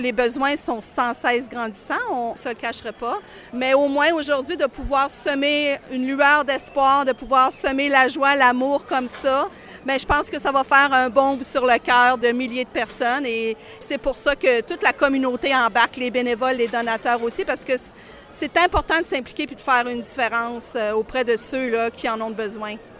C’est ce qu’elle a déclaré au FM 103,3 ce mercredi, au cours de la traditionnelle distribution de paniers et de cadeaux de Noël à l’Église Nouvelle Vie.